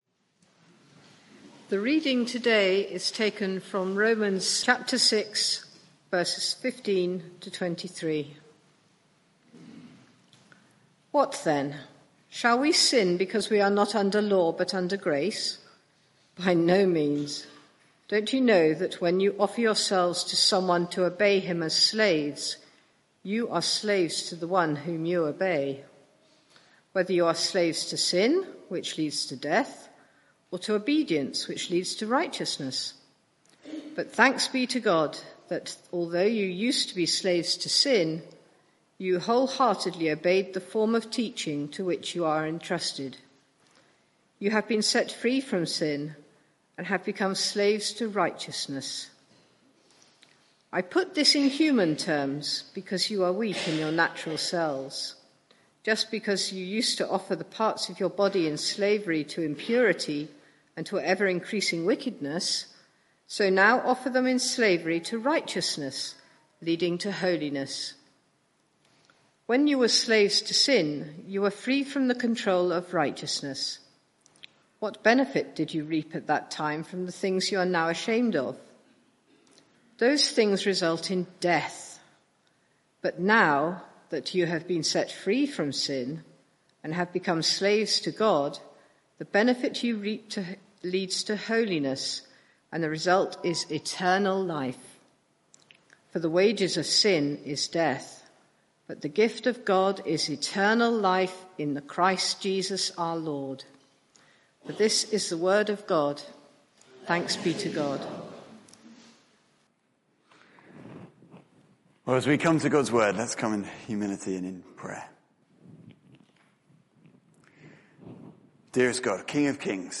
Media for 9:15am Service on Sun 26th Nov 2023 09:15 Speaker
Sermon (audio)